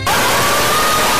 Play, download and share Screamuwu original sound button!!!!
screamuwu.mp3